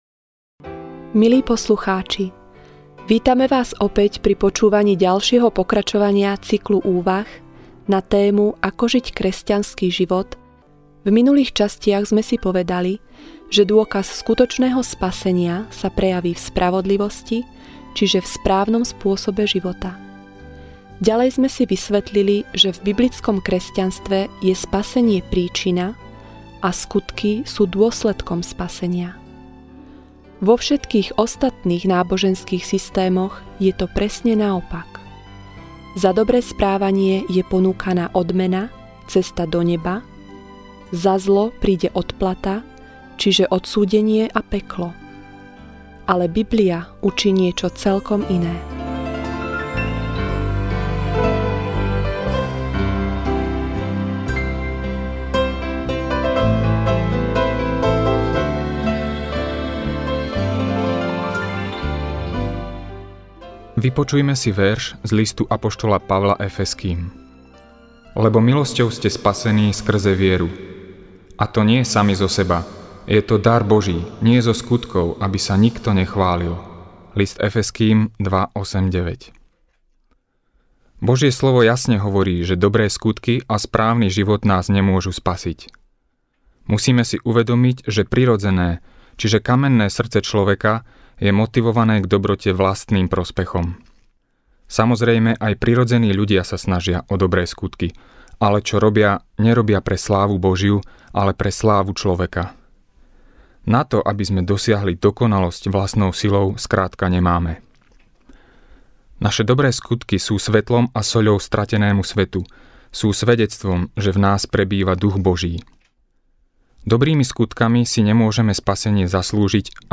E-mail Podrobnosti Kategória: Úvahy, zamyslenia Séria